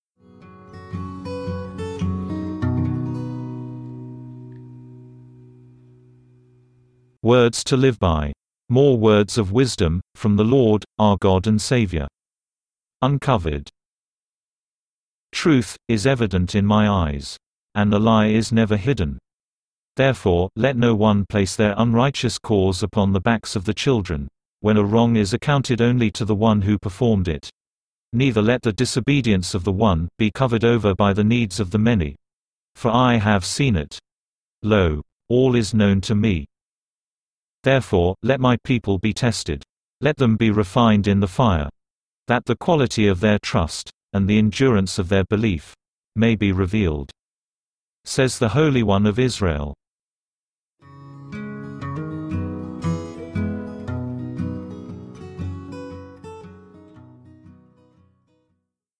File:WTLB 123 Uncovered (read by text-to-speech).mp3 - The Volumes of Truth
WTLB_123_Uncovered_(read_by_text-to-speech).mp3